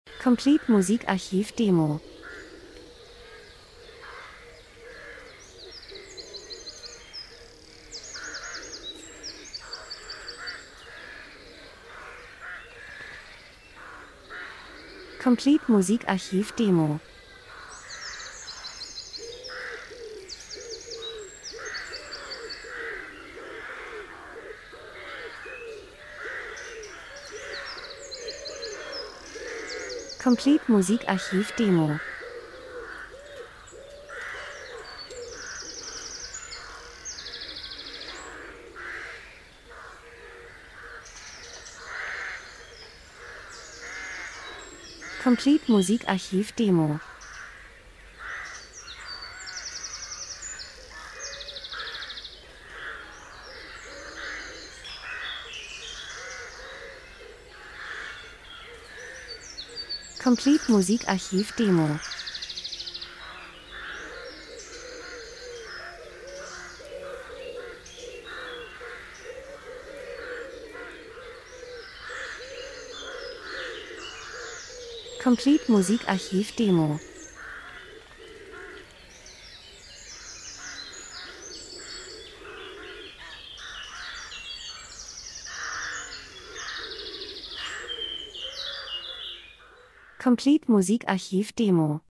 Herbst -Geräusche Soundeffekt Vögel, Krähen Tauben 01:30